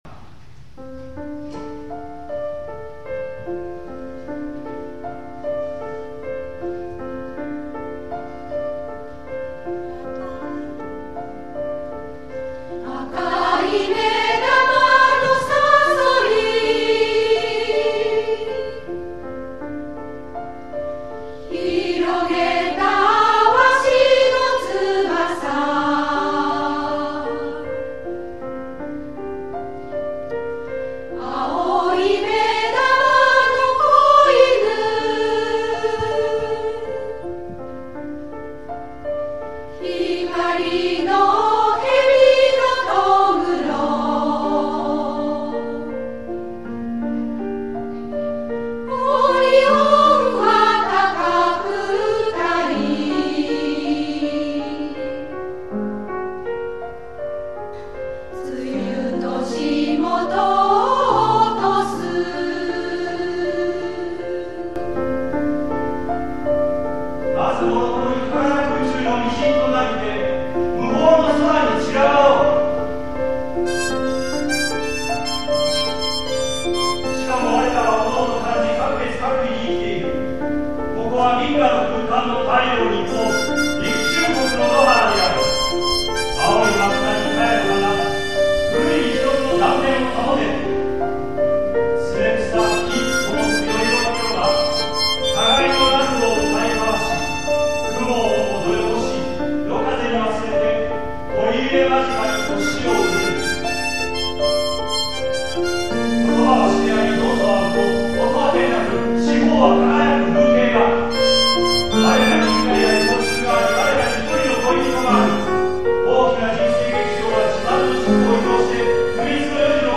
女声合唱団「道」 お知らせ
女声合唱団「道」の第３回コンサートを平成１９年６月１６日（土）に開きました。
第三ステージ「宮澤賢治〜朗読と歌、合唱による」